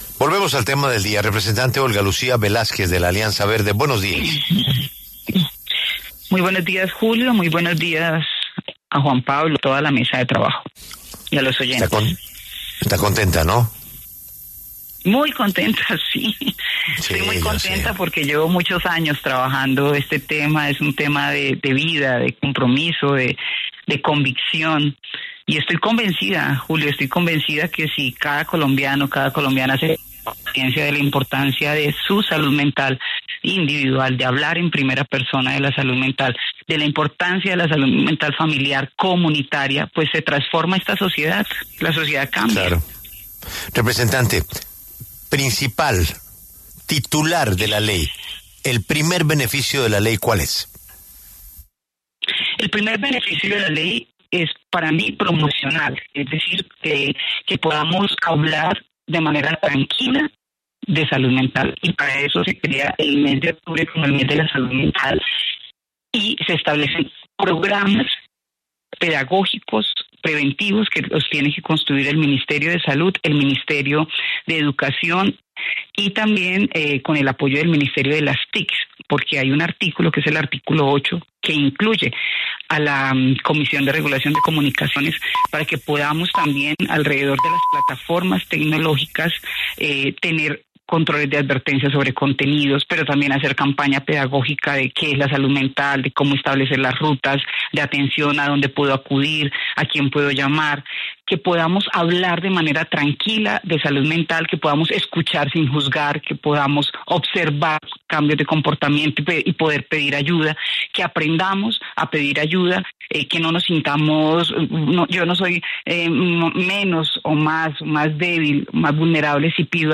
La representante de la Alianza Verde, Olga Lucía Velásquez, autora de la Ley de Salud Mental, pasó por los micrófonos de La W.